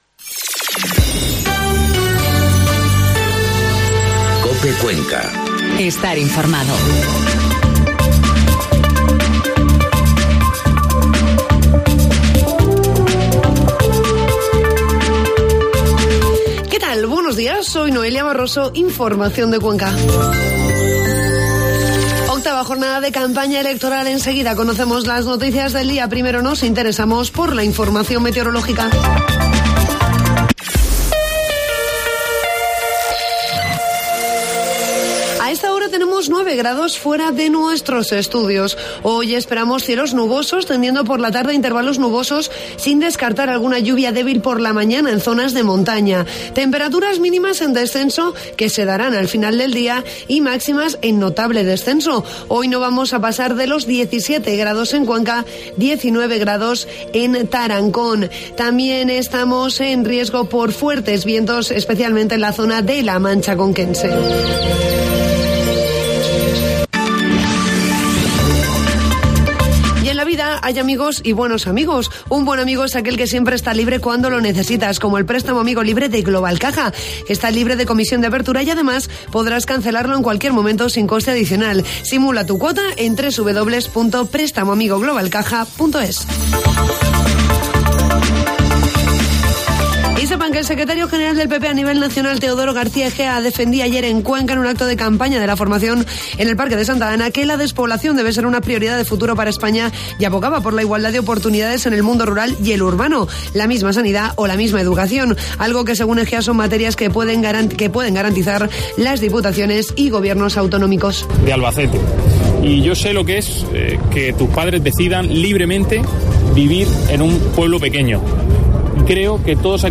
Informativo matinal COPE Cuenca 17 de mayo